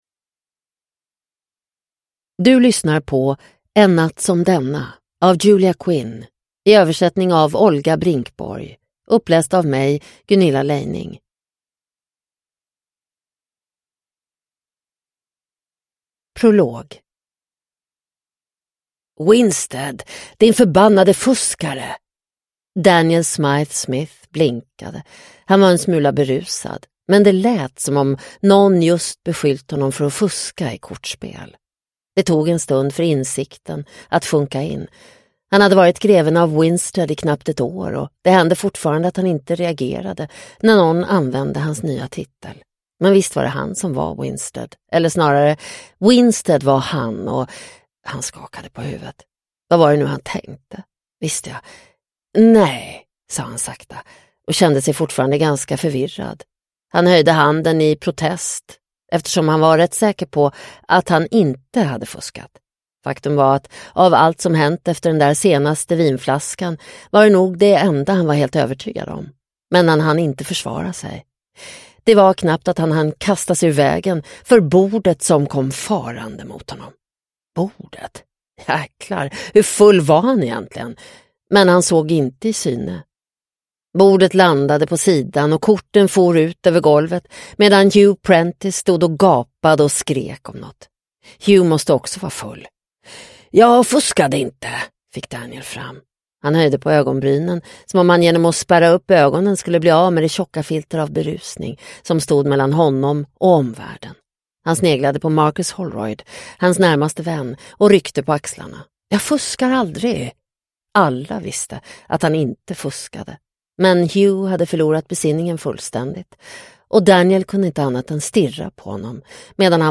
En natt som denna – Ljudbok – Laddas ner